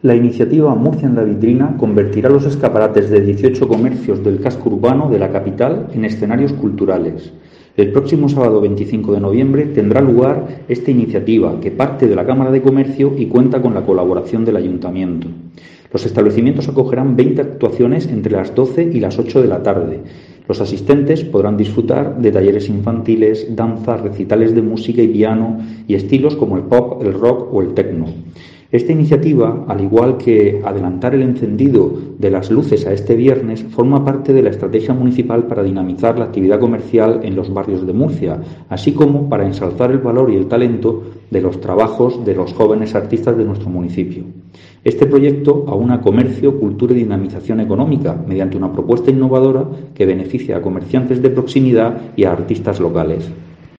Jesús Pacheco, concejal de Comercio